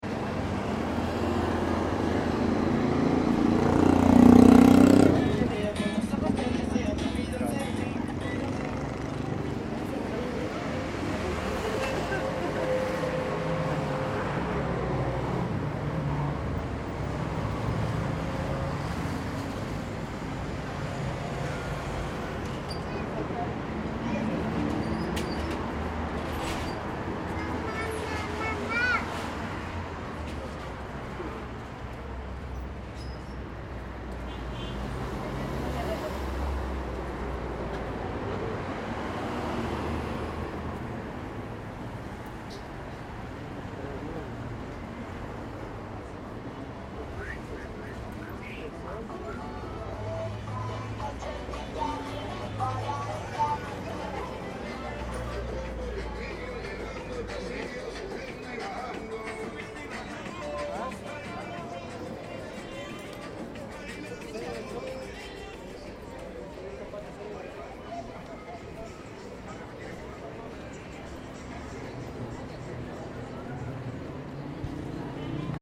Promenons-nous dans les rues et imprégnons-nous de l’ambiance sonore :
LPcalle1.mp3